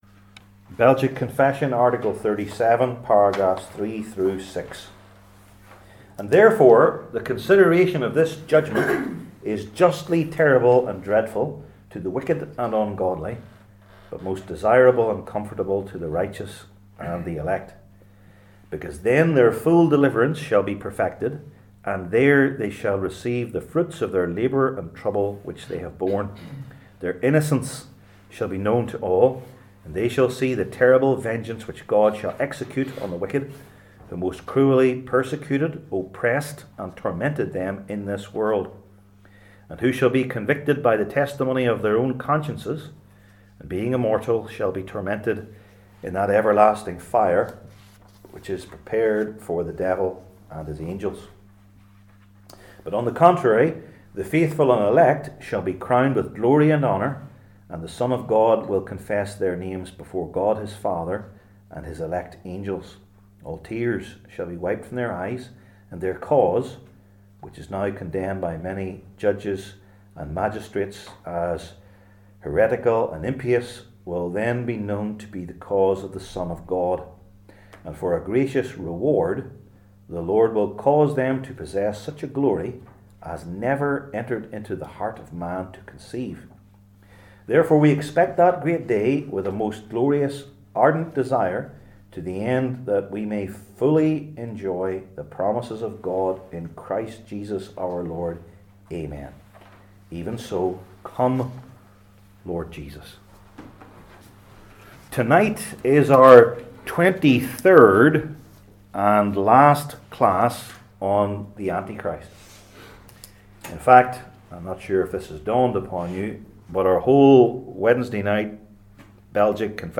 The Last Judgment Passage: Daniel 7:1-12, 19-28 Service Type: Belgic Confession Classes THE LAST JUDGMENT …